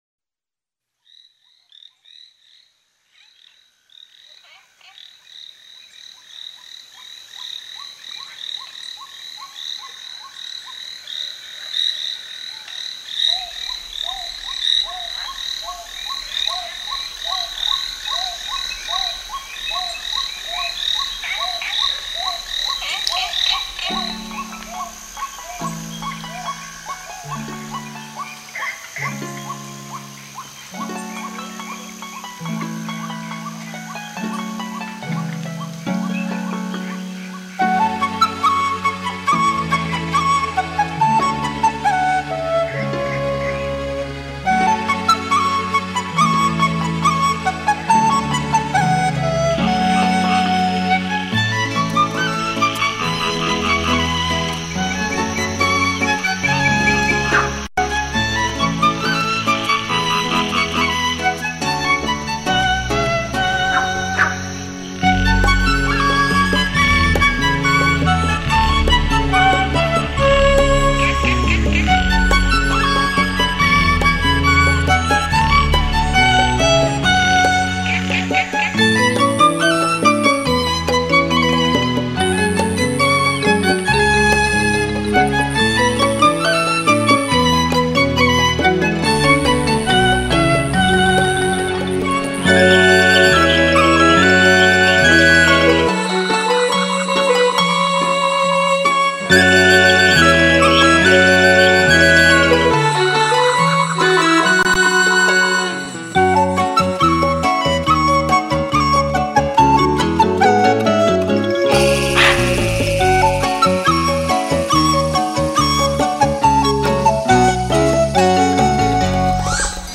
◎母帶經FURUTECH RL-30專業消磁器處理，音色通透純淨。
擁有全世界第一張台灣大自然音樂